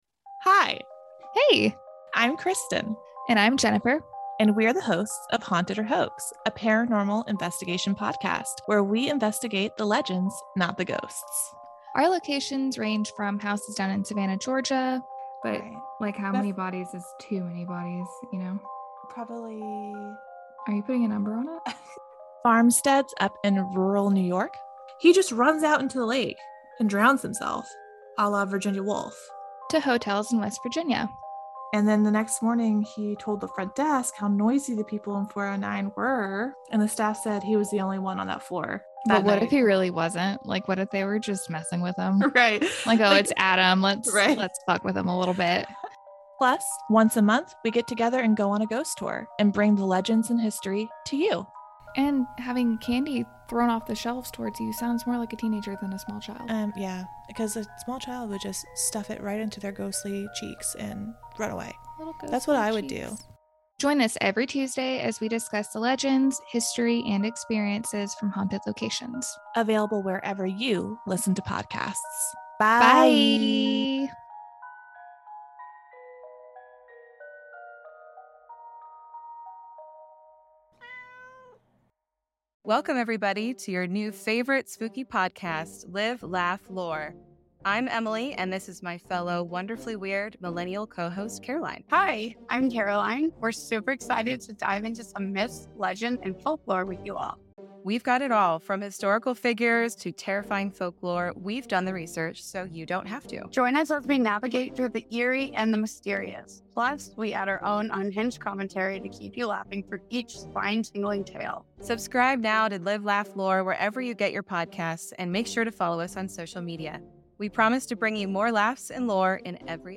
The Activity Continues is a podcast where three soul friends talk about all things paranormal. Ghosts, hauntings, psychics, movies and TV shows (especially The Dead Files) and other spooky and spooky-adjacent things as well. We also interview people in the paranormal community as well as past Dead Files clients and other interesting people.